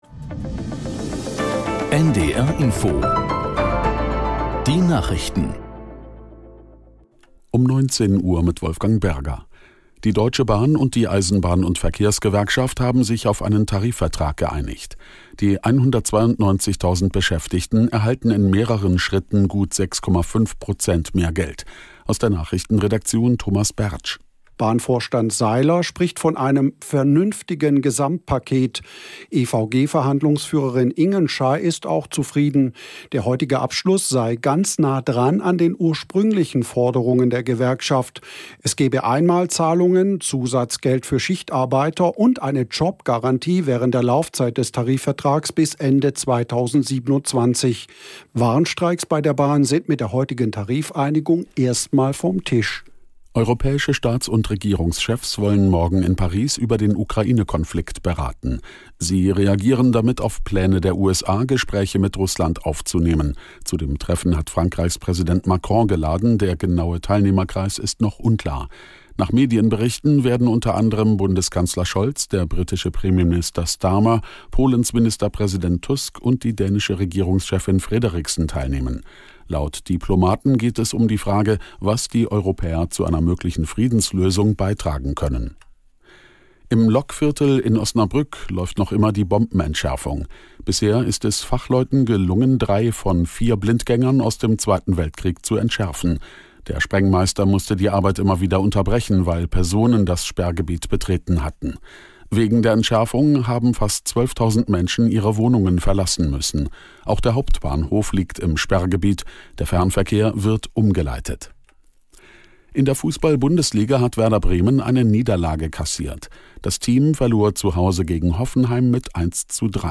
Nachrichten - 16.02.2025